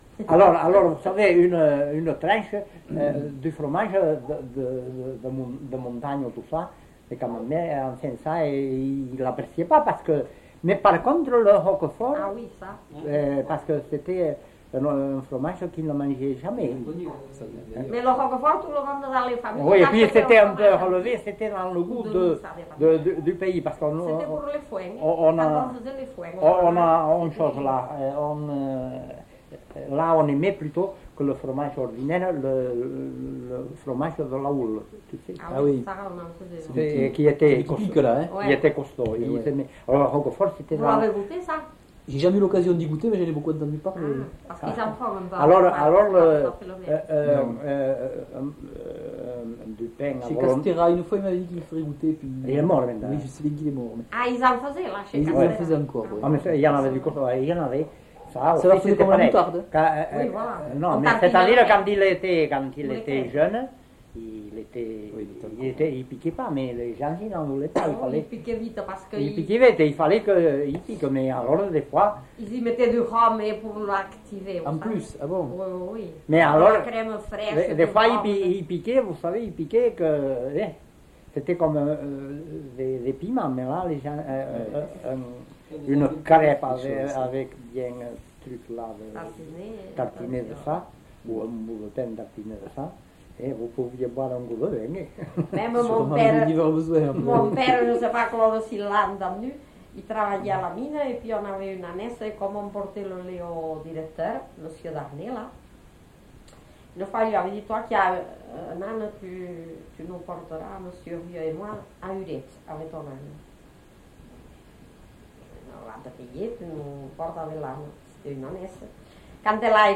Lieu : Eylie (lieu-dit)
Genre : témoignage thématique